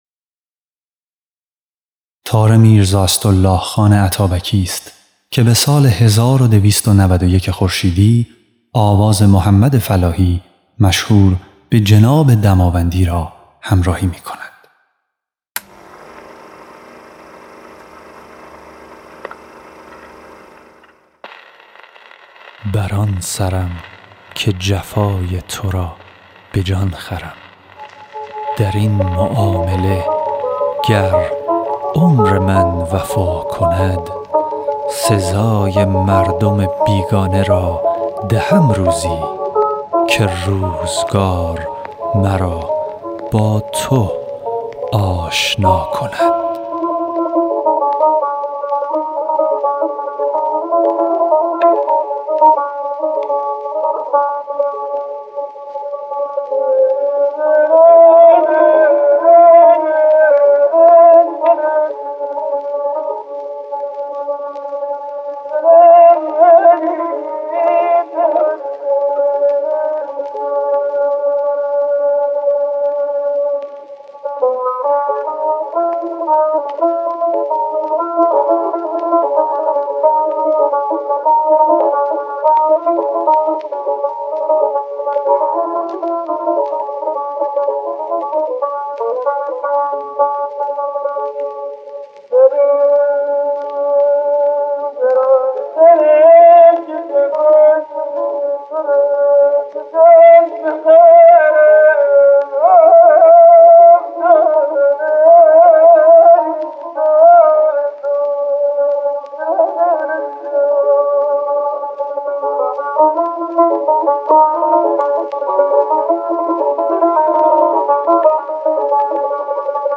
ضبط سری اول آثار
خواننده
نوازنده تار